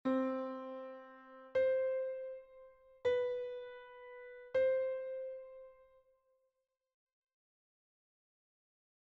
Exercise 1: C, C, B.